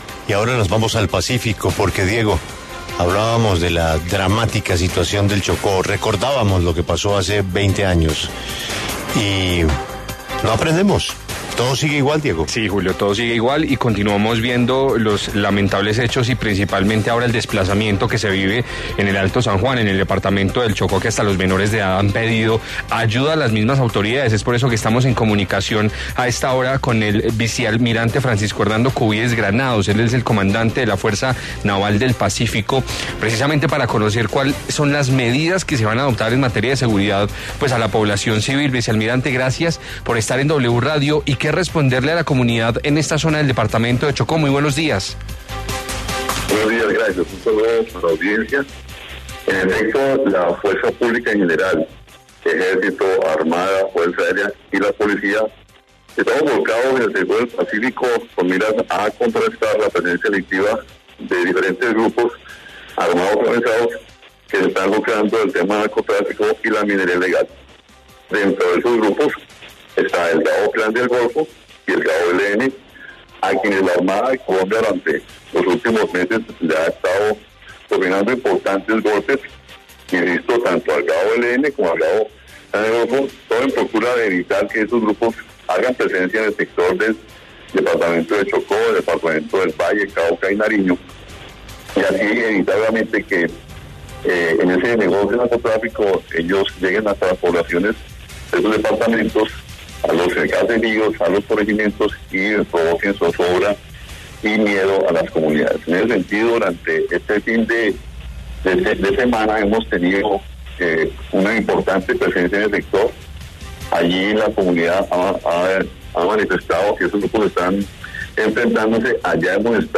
En diálogo con La W, el vicealmirante Francisco Cubides se refirió a las medidas adoptadas por la fuerza pública para proteger a la población de esta región del Chocó.